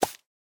brush_armadillo2.ogg